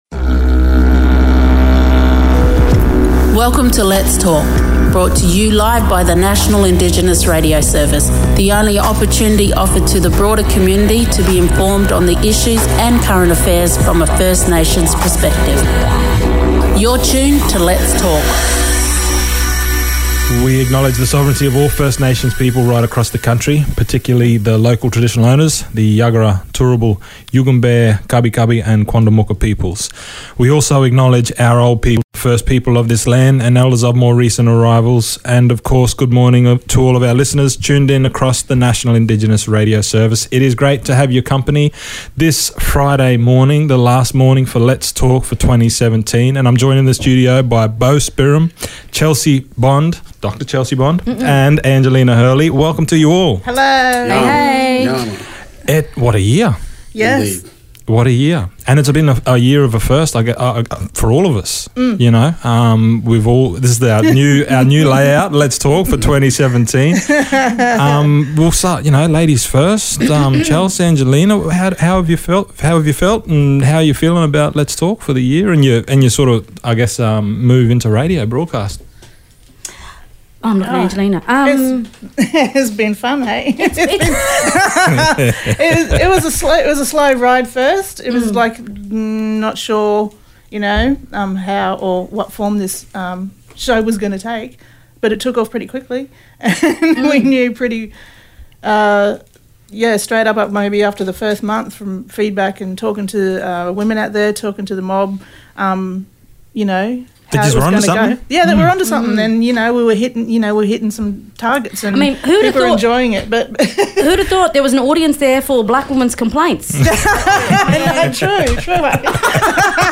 Its an all star line up today as all of our regular hosts gather in the one studio to discuss the year of 2018 in Indigenous affairs.